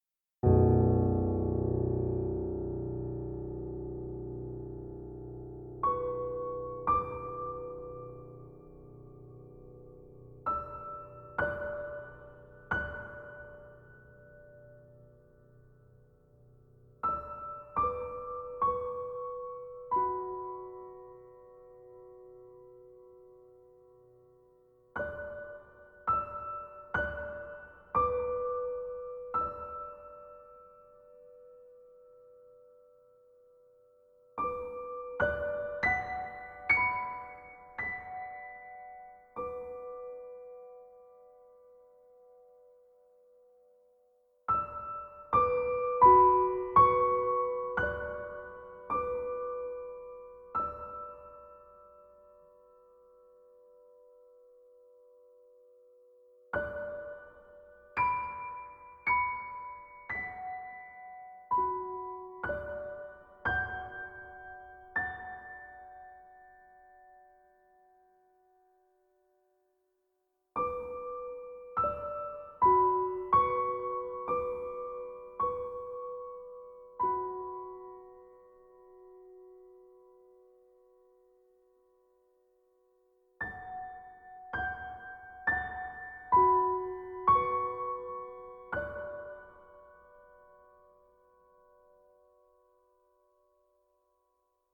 Tónica: Si